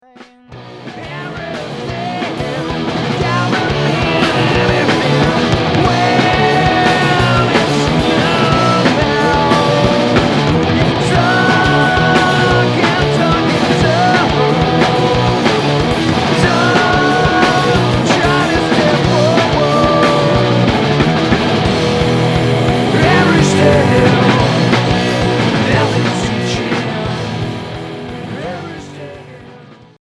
Noisy rock